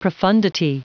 Prononciation du mot profundity en anglais (fichier audio)
Prononciation du mot : profundity